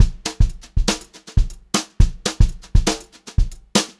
120HRBEAT2-L.wav